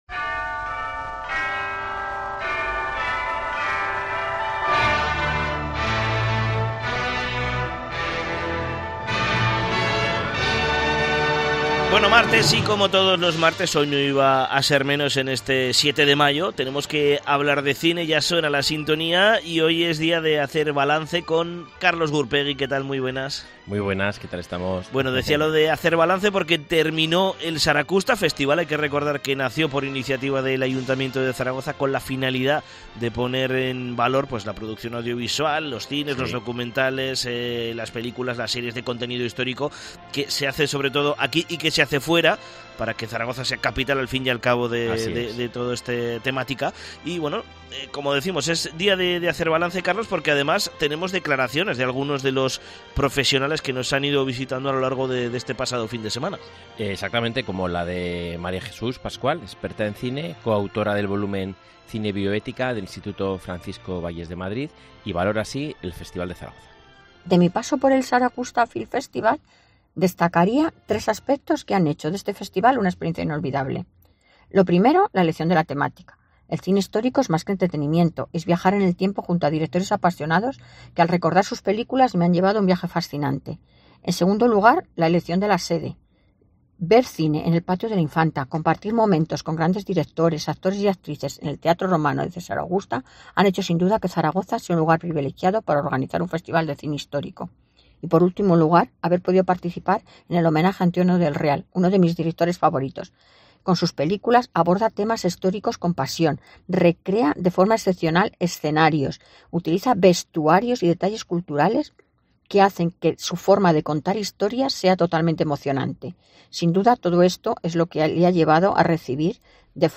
Esta semana en nuestra sección de cine hablamos con Viggo Mortensen